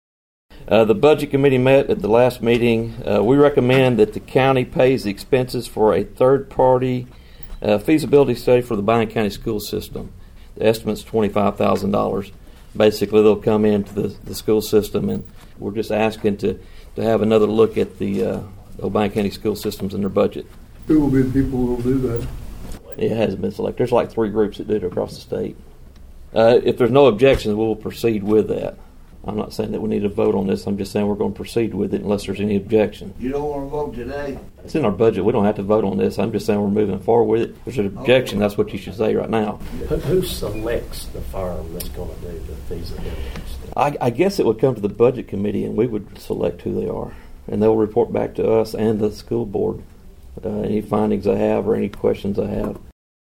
The request to fund the study was added to the agenda during the County Commission meeting on Tuesday, and was addressed by Budget Committee Chairman Sam Sinclair Jr.(AUDIO)